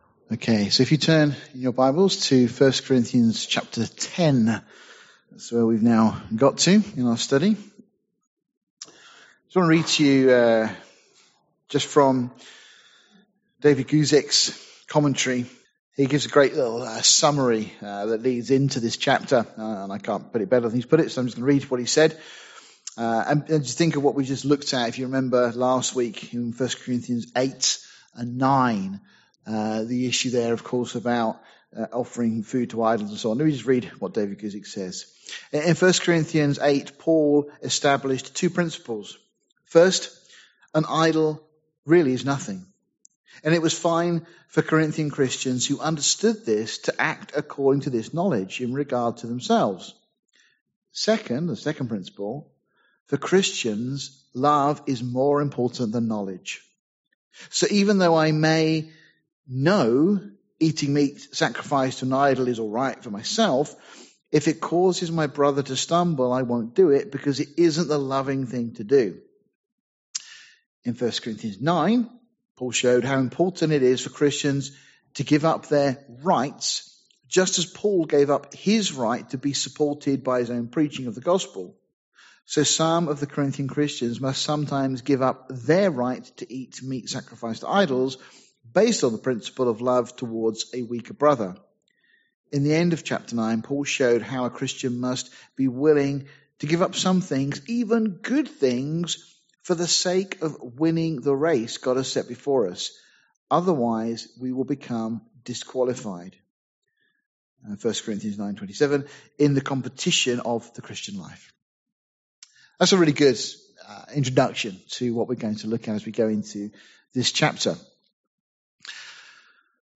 This study of 1 Corinthians hapter 10:1-12